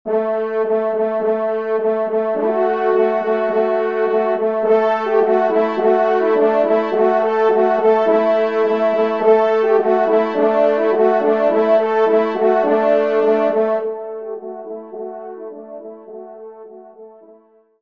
Genre :  Divertissement pour Trompe ou Cor et Piano
3e Trompe